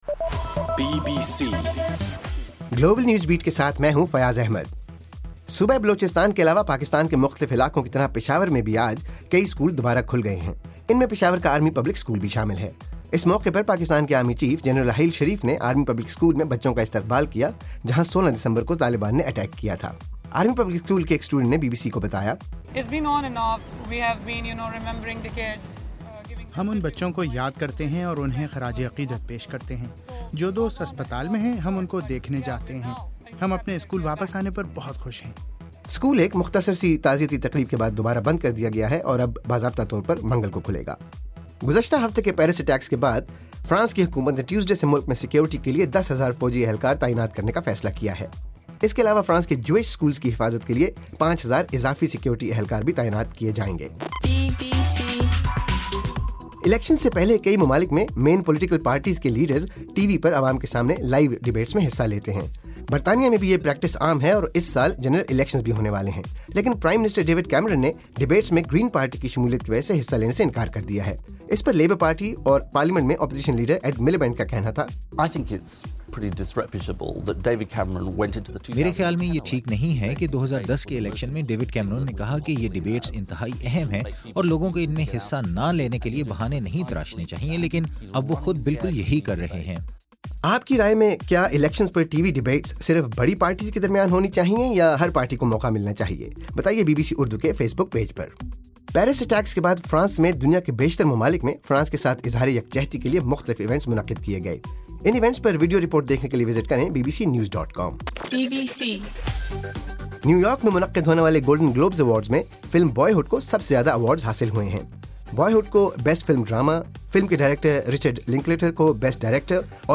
جنوری 12: رات 8 بجے کا گلوبل نیوز بیٹ بُلیٹن